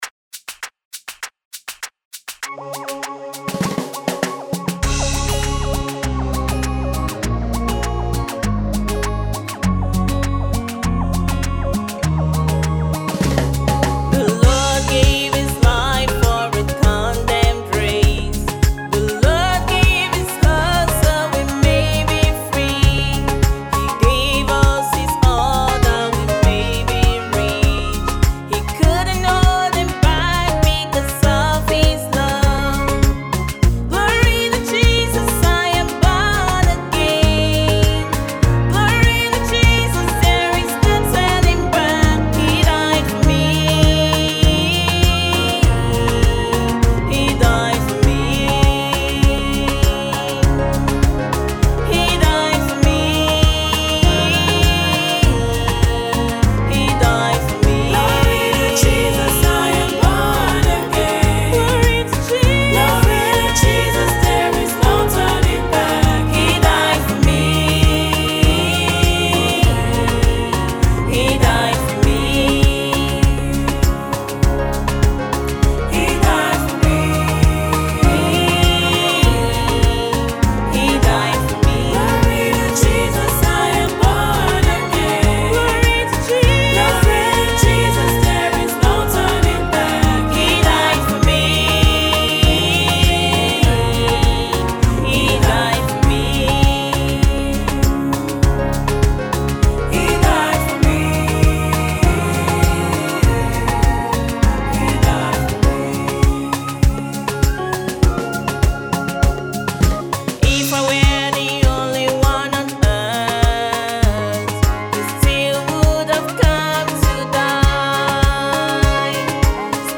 Fast rising Nigeria Gospel minister and songwriter